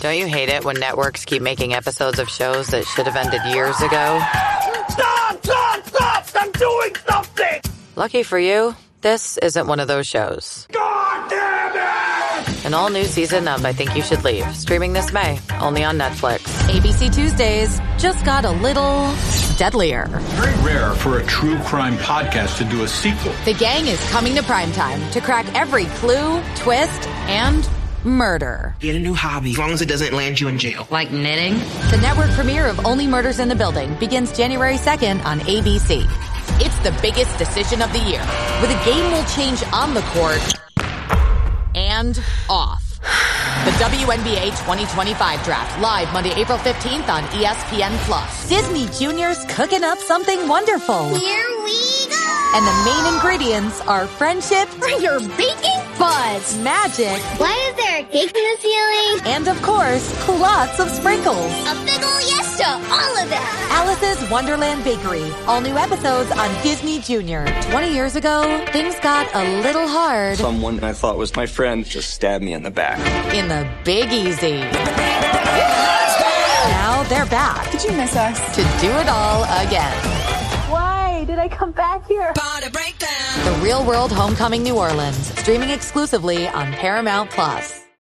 Inglés (Estados Unidos)
Demo comercial
Micrófono: Sennheiser 416
Contralto
ConversacionalAmistosoCálidoConfiableInteligenteAtractivoSinceroGenuinoInformativoDiversiónDivertidoComedianteCarismáticoAuténticoOptimista